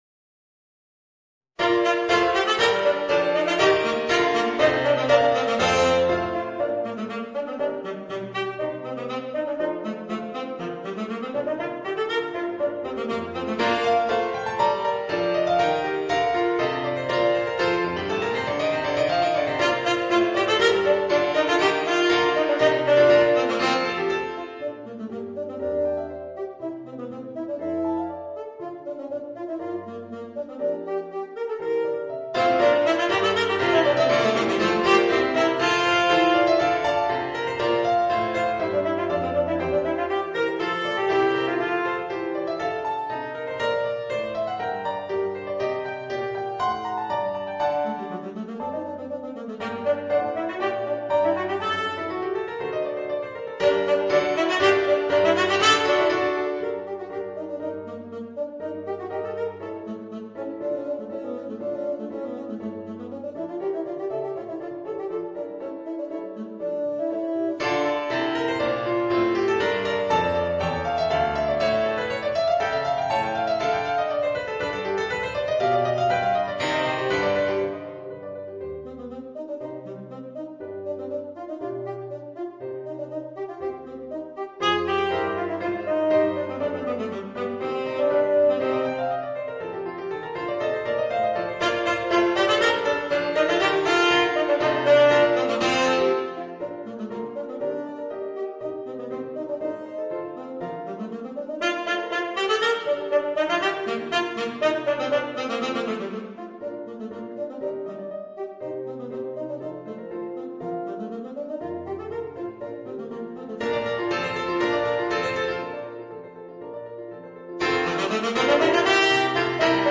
Voicing: Tenor Saxophone and Organ